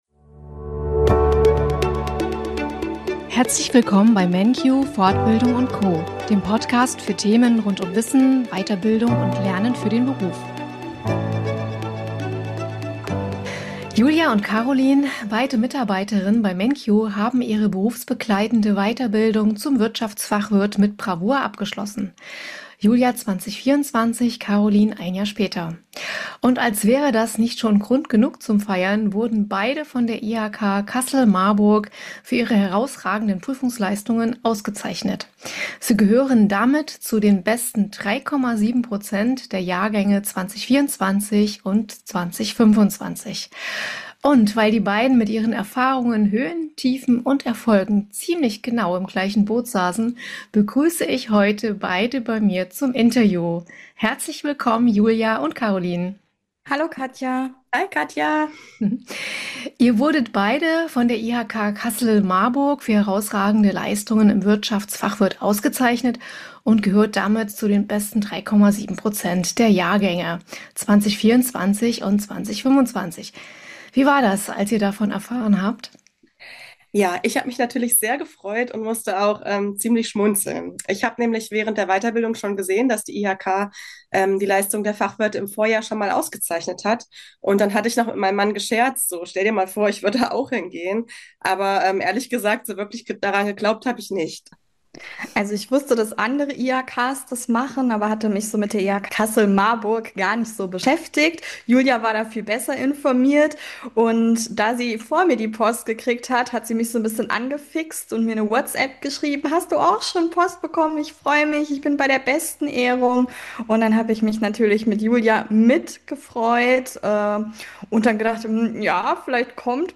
Im Interview berichten sie von Lernen, Motivation und Teamgeist.